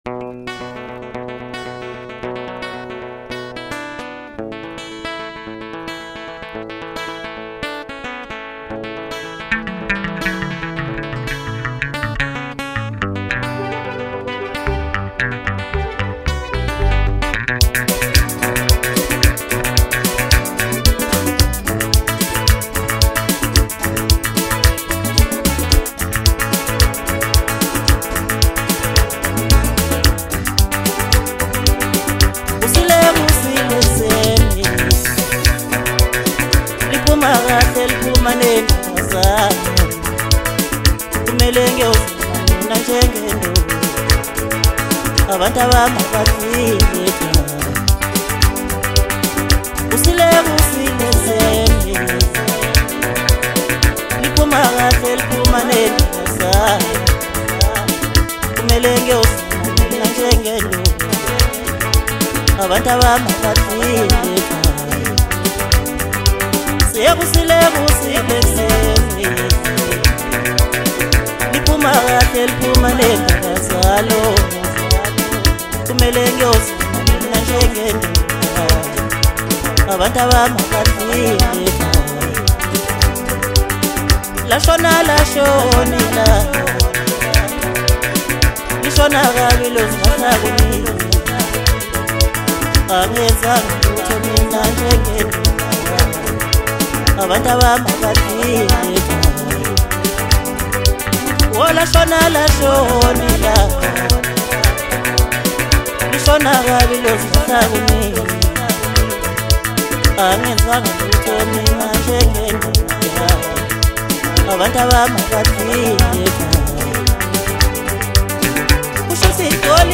Home » Maskandi » DJ Mix » Hip Hop
South African singer-songsmith